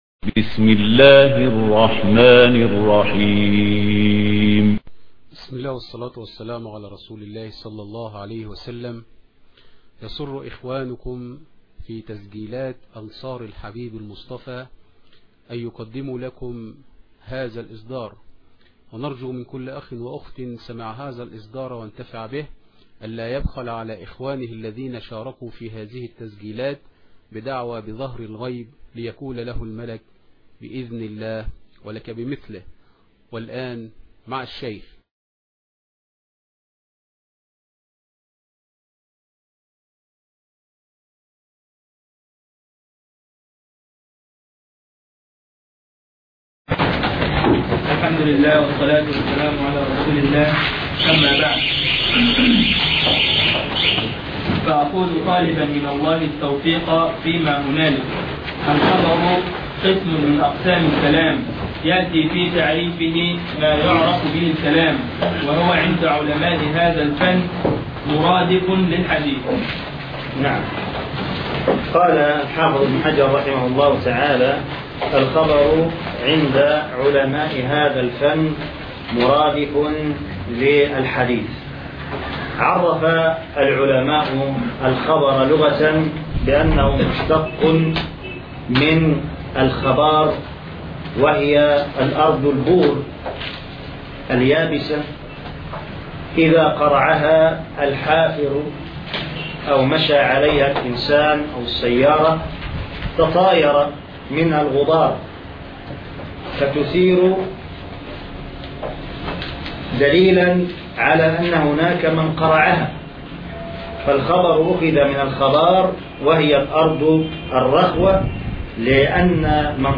الدرس 3 ( شرح كتاب نزهة النظر